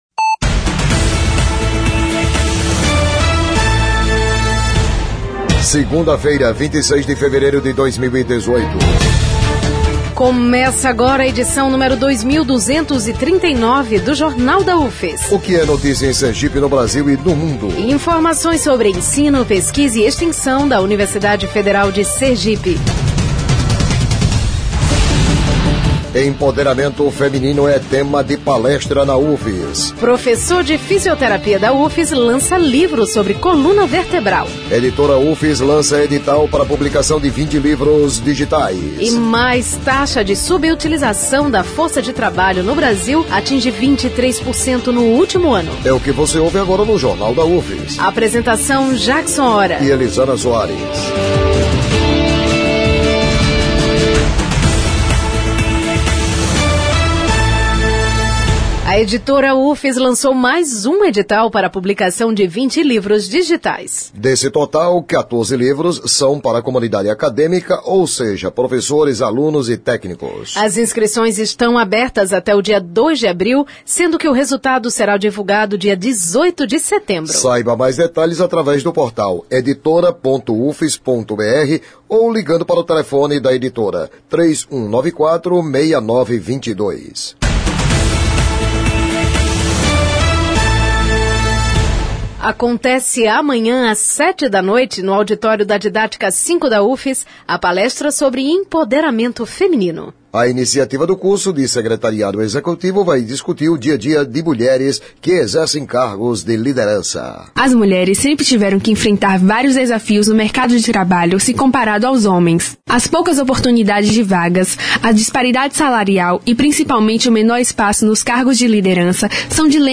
O Jornal da UFS desta segunda-feira, 26, traz detalhes sobre a mesa redonda de empoderamento feminino, que acontece nesta terça-feira, no campus de São Cristóvão. A ação do curso de Secretariado Executivo vai discutir a rotina de mulheres com cargos de liderança. O noticiário vai ao ar às 11h na Rádio UFS, com reprises às 17h e 22h.